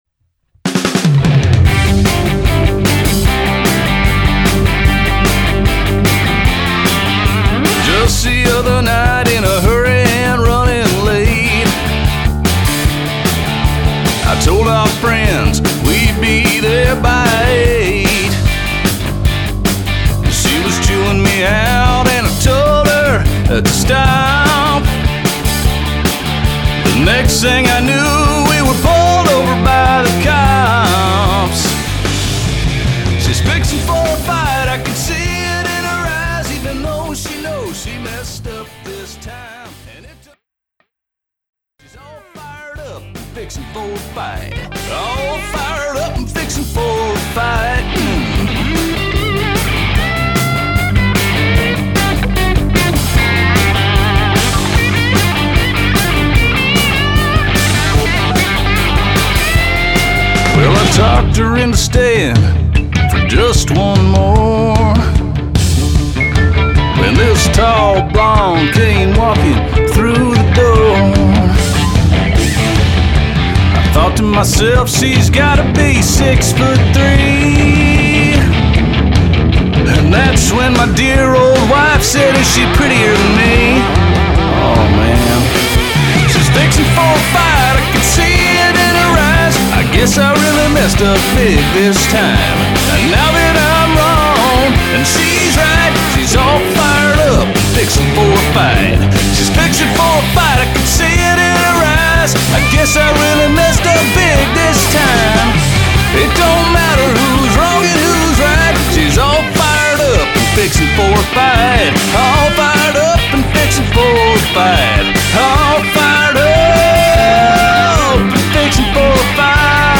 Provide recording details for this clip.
Based on the amplifier's settings, the actual power hitting the speaker was less than 0.5 watt. ARACOM VRX22 Head, (22 watts, 6V6 equipped), 1x12 Extension Cabinet with a Celestion G12H, Nash Strat with a Duncan PAF in the bridge, Sennheiser 906 mic